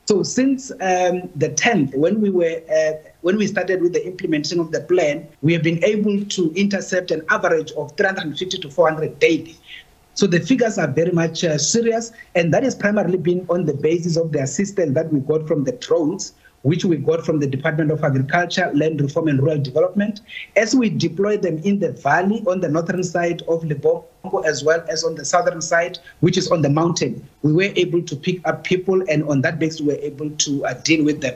Die Grensbestuursowerheid se kommissaris, Michael Masiapato, het aan die SABC gesê hulle sal bedrywighede na die Mananga-grens herlei indien die situasie onhanteerbaar word.